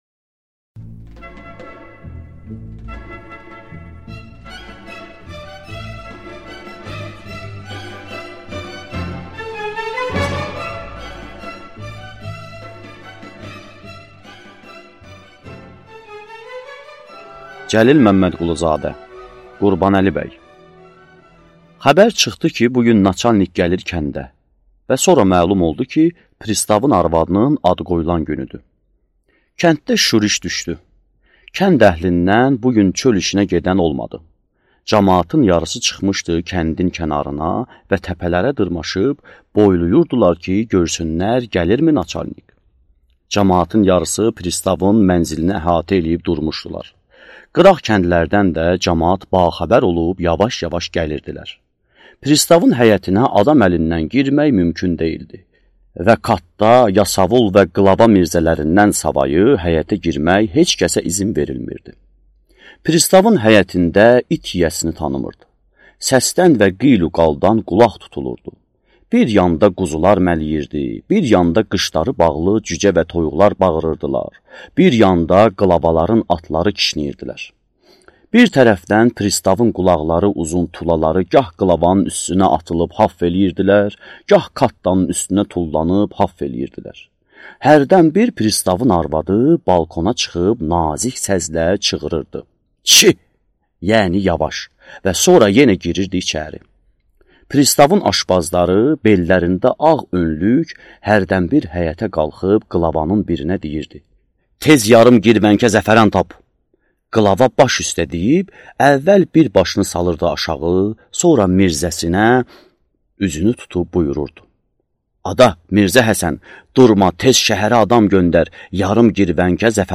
Аудиокнига Qurbanəli bəy | Библиотека аудиокниг